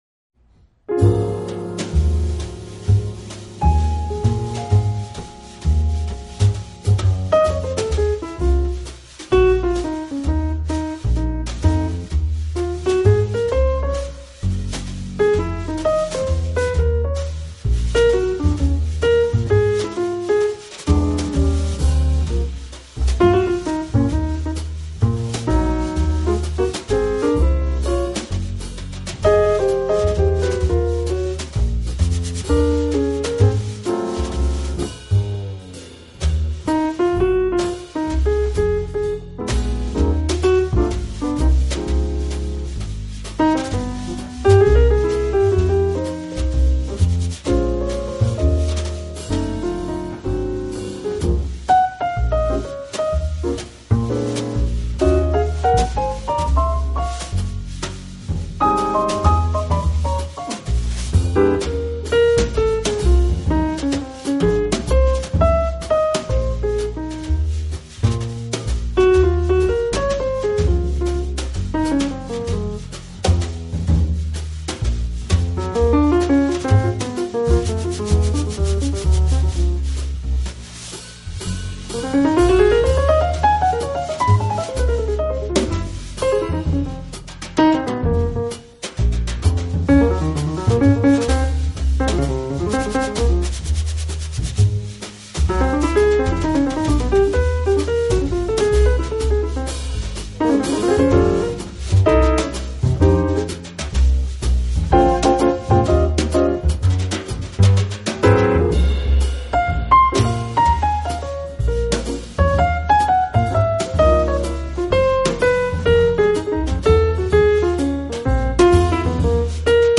音乐类型：Jazz
但他懂得掌握钢琴浪漫与甜美的特质，而且将这些特质发挥得恰到好处，我猜想满